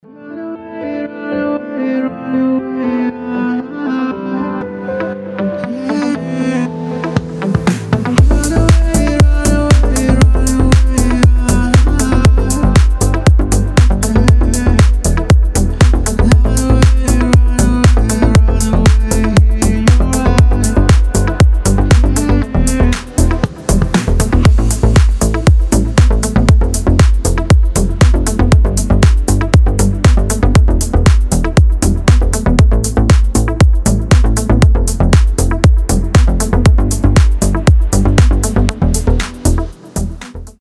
• Качество: 320, Stereo
мужской вокал
deep house
чувственные
nu disco